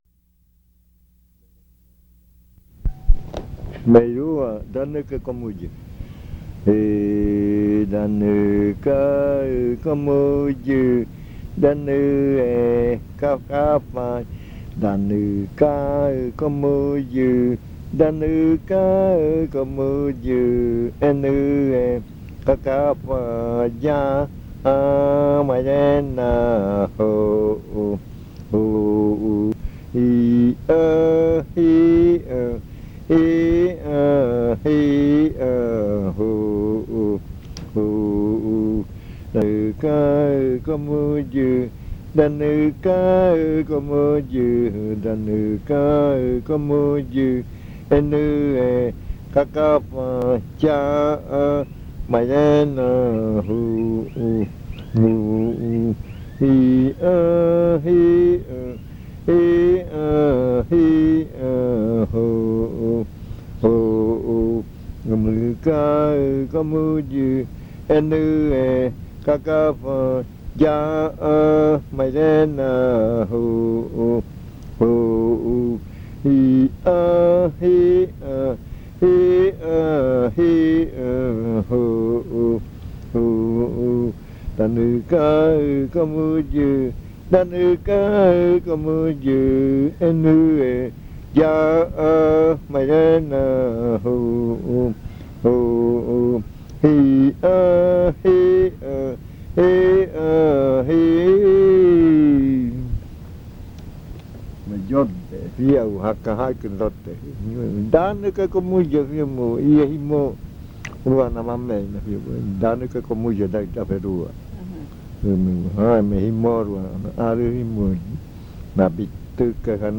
Leticia, Amazonas
Cantos de yuakɨ